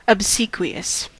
obsequious: Wikimedia Commons US English Pronunciations
En-us-obsequious.WAV